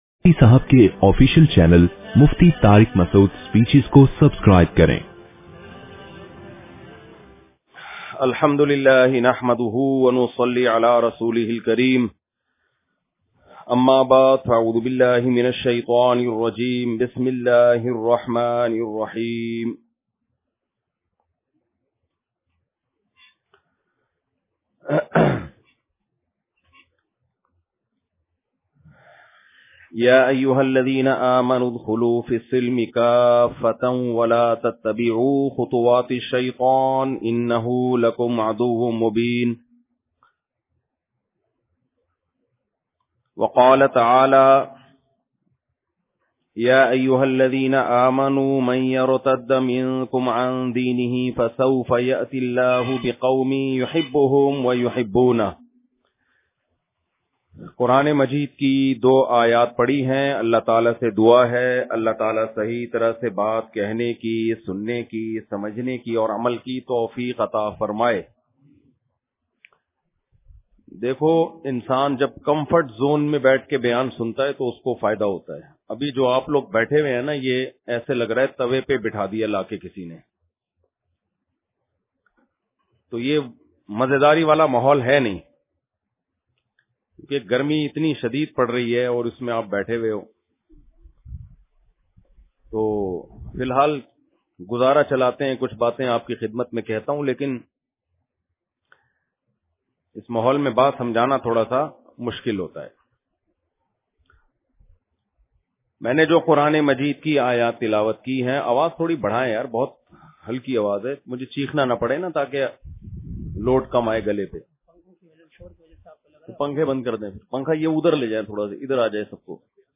Ashura Bayan
Ashura Par Khususi Bayan 2024 _ Mufti Tariq Masood Speeches.mp3